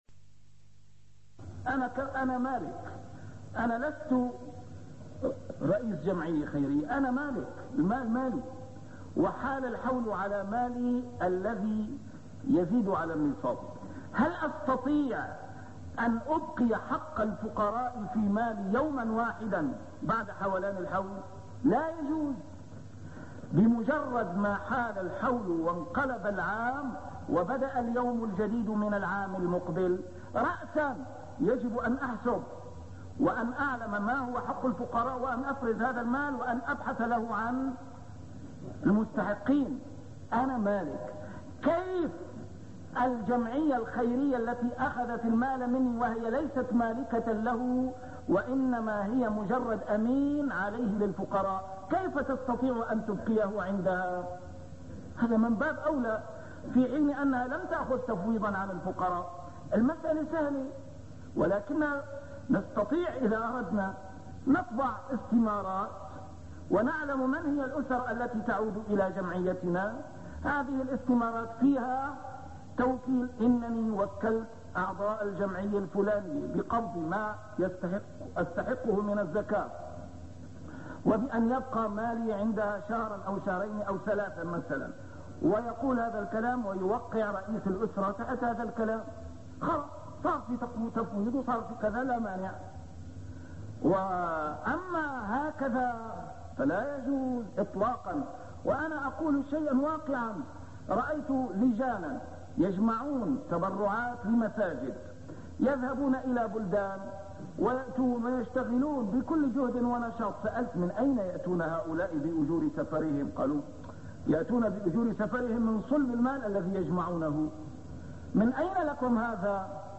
A MARTYR SCHOLAR: IMAM MUHAMMAD SAEED RAMADAN AL-BOUTI - الدروس العلمية - شرح الأحاديث الأربعين النووية - تتمة شرح الحديث الثاني (بينما نحن جلوس عند رسول الله صلى الله عليه وسلم) 9